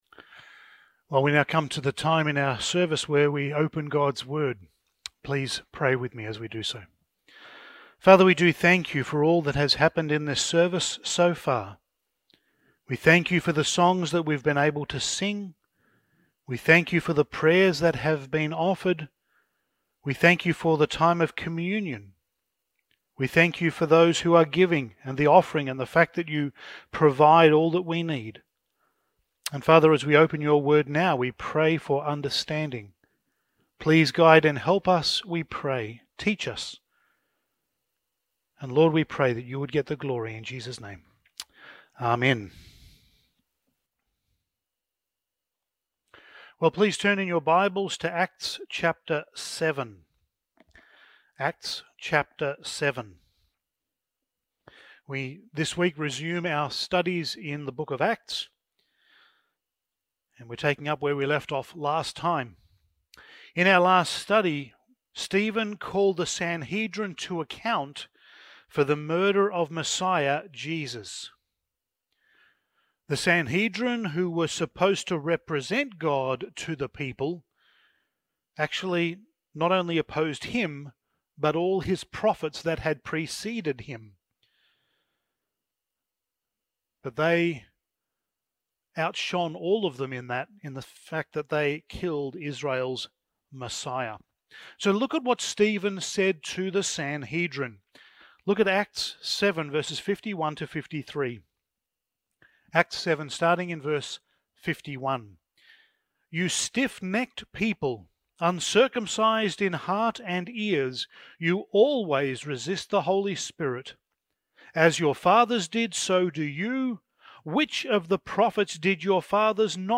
Passage: Acts 8:1-8 Service Type: Sunday Morning